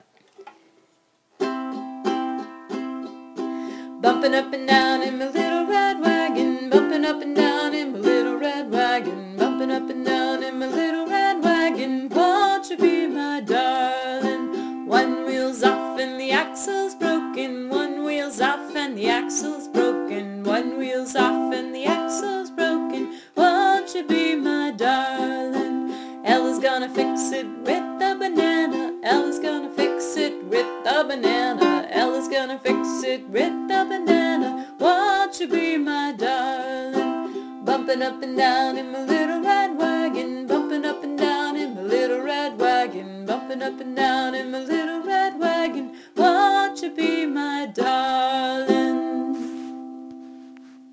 I played it on the ukulele, which is easy because you just alternate between C and G7.